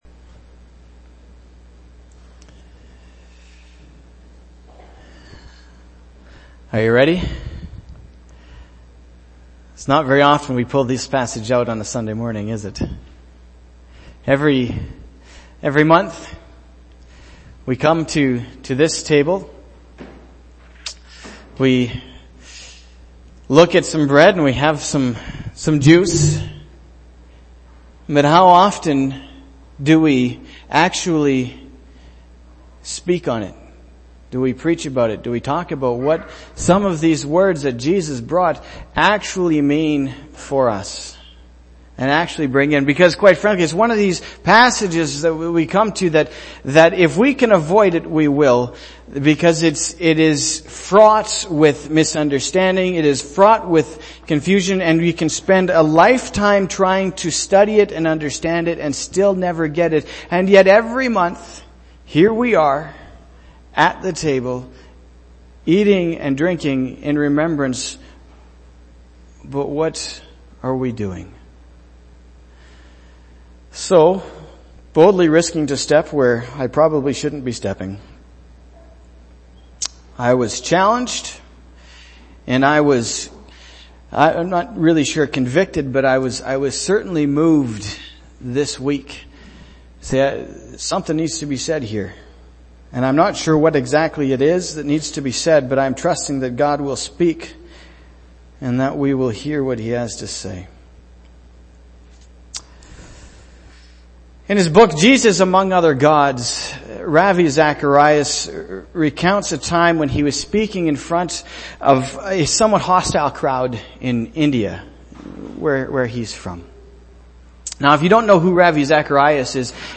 April-3-2016-sermon.mp3